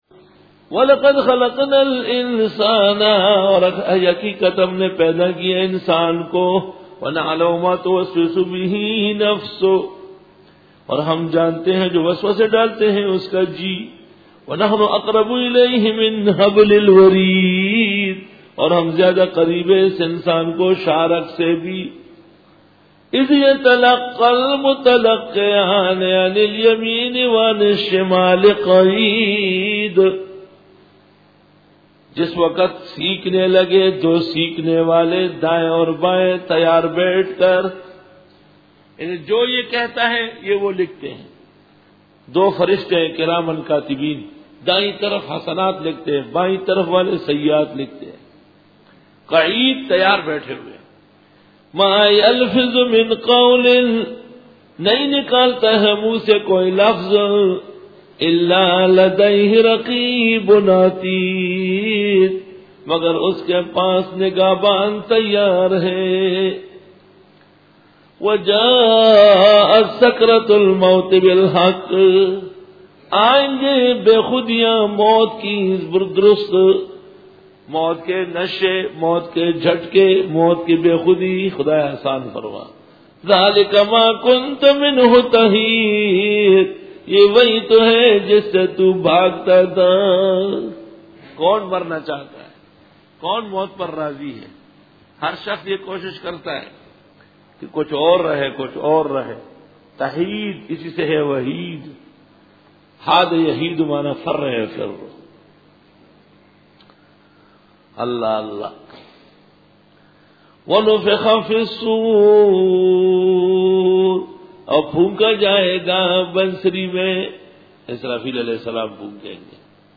سورۃ قٓ رکوع-02 Bayan